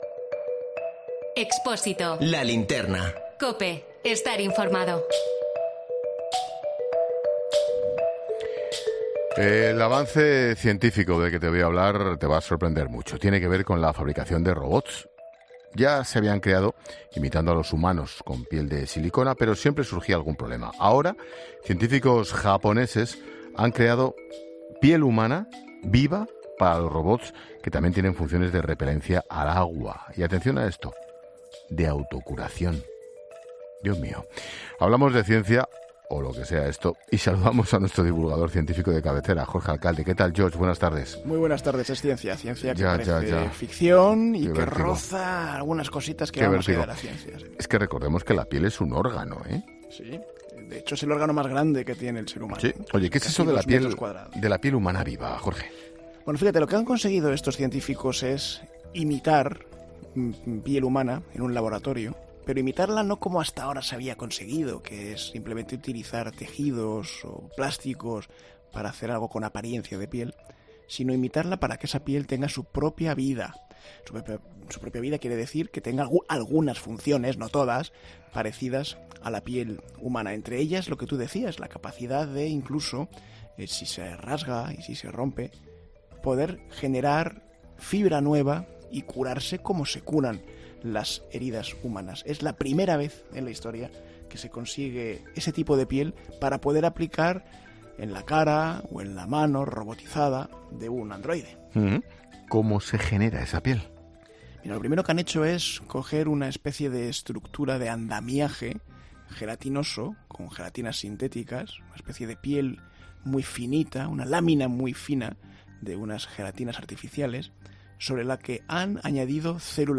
Un experto explica la creación de piel humana viva para robots: "Transmitir una caricia por Internet"